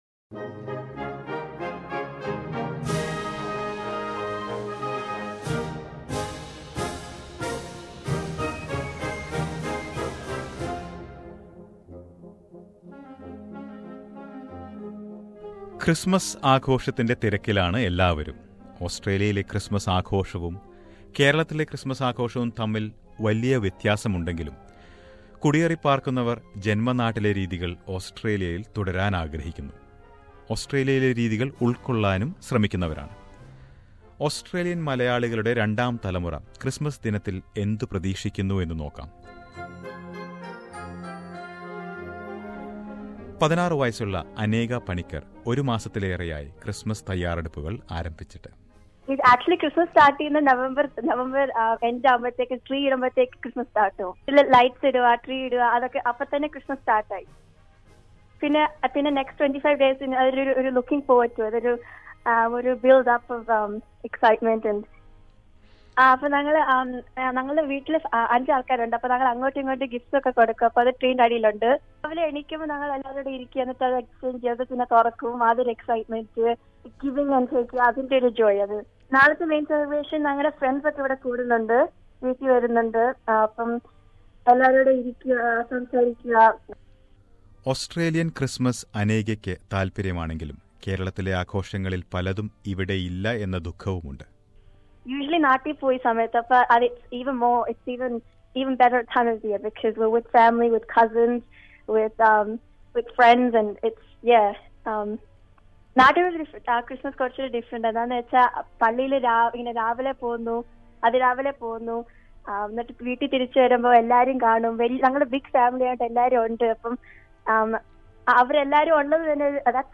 Listen to some youngsters sharing their views on Christmas in Australia.